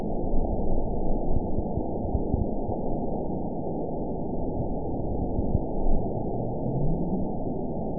event 920355 date 03/18/24 time 03:10:05 GMT (1 year, 1 month ago) score 9.62 location TSS-AB03 detected by nrw target species NRW annotations +NRW Spectrogram: Frequency (kHz) vs. Time (s) audio not available .wav